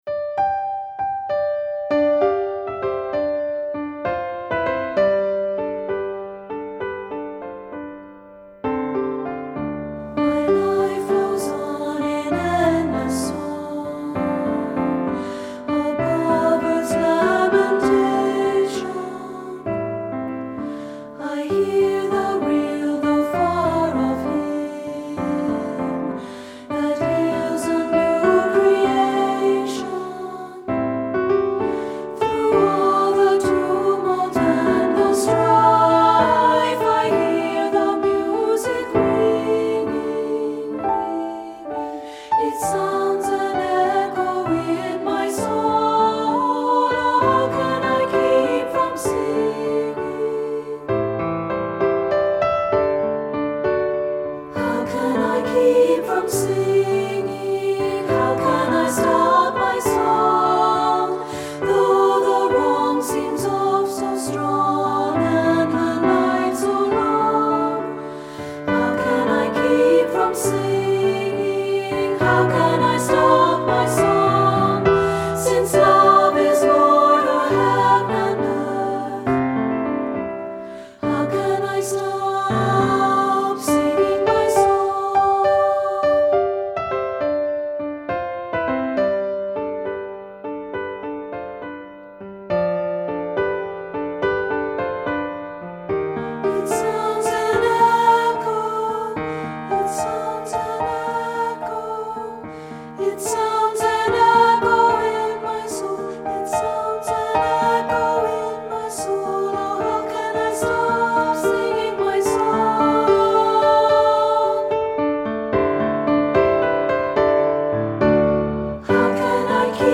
Unison, optional Two-Part, accompanied
• Voice 1
• Voice 2
• Piano
Studio Recording
Ensemble: Unison and Two-Part Chorus
Key: G major
Accompanied: Accompanied Chorus